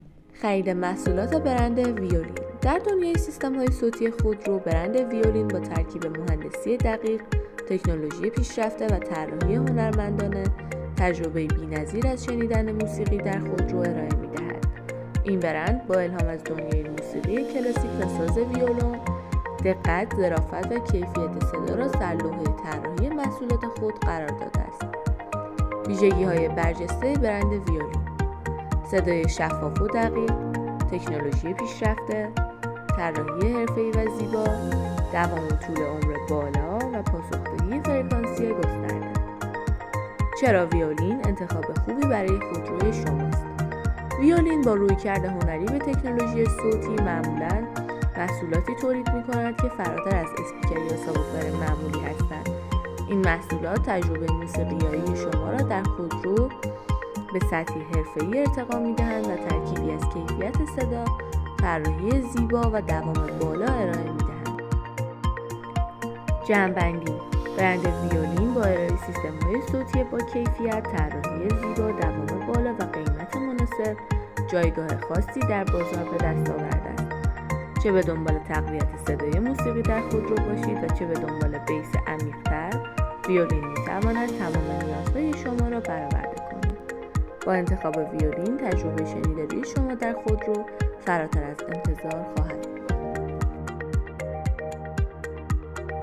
ویولین
ویولین.m4a